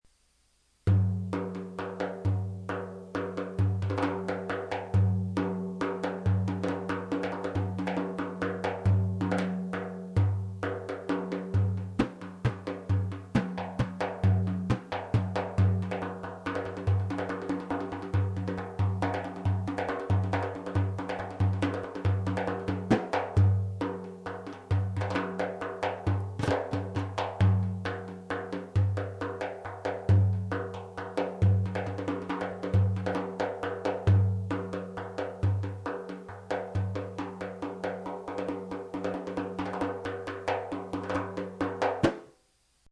The universal frame drum use by cultures all over the world.
This one is Turkish and measures about 26 inches in diameter.
bendir.mp3